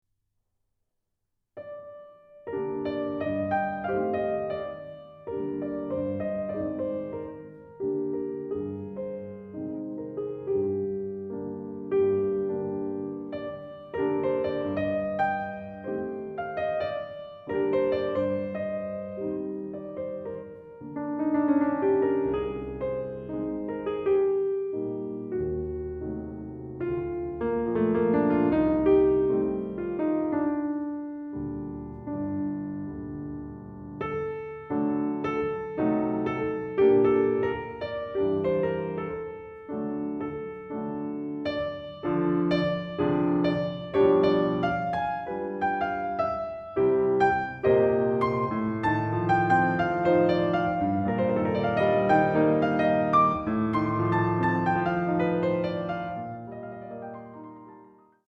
Nocturne in G minor